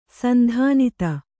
♪ sandhānita